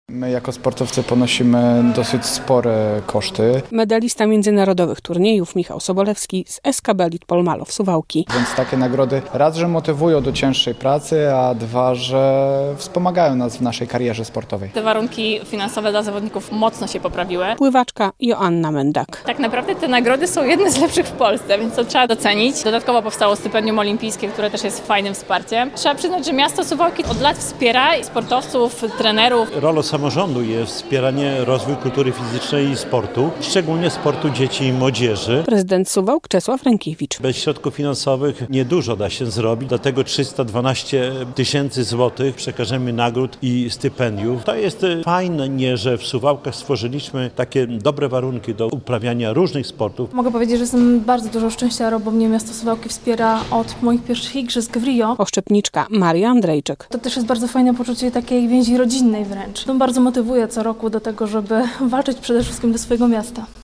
Nagrody prezydenta Suwałk dla sportowców - relacja